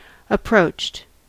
Ääntäminen
Ääntäminen US Haettu sana löytyi näillä lähdekielillä: englanti Approached on sanan approach partisiipin perfekti.